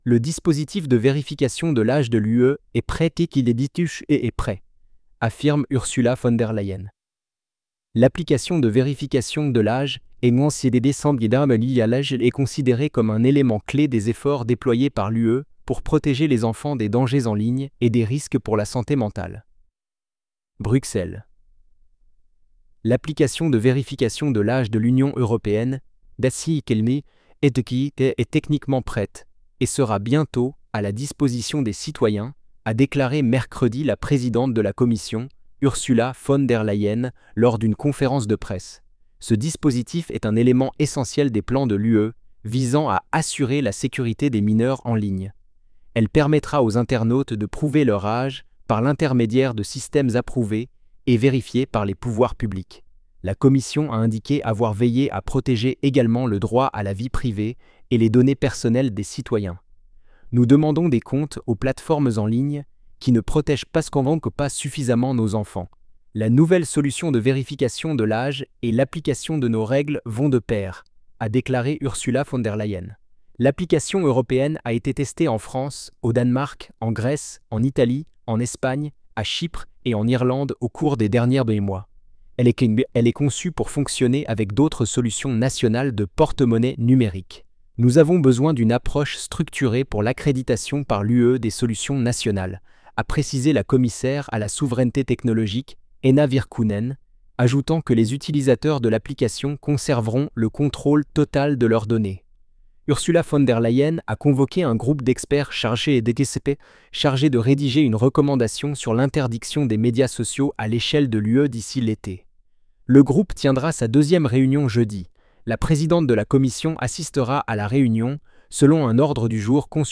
Synthèse vocale générée par l’IA